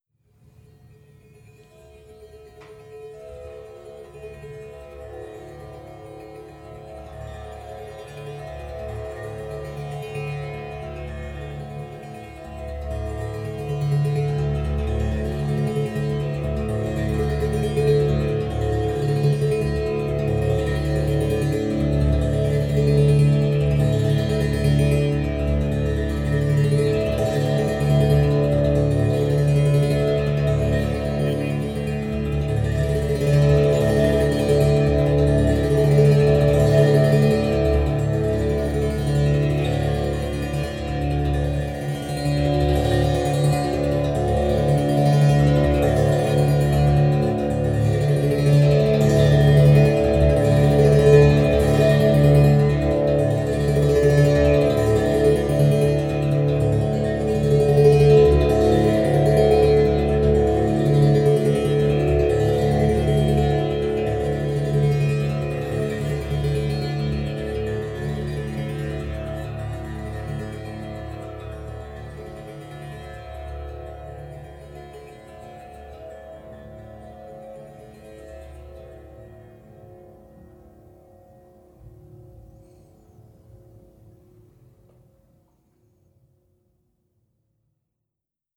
• monolina monochord melody D.wav
monolina_monochord_melody_D_QXD.wav